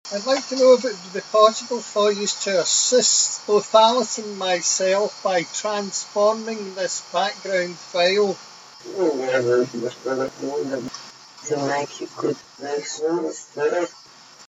Voice EVP
The background used was reversed Bulgarian.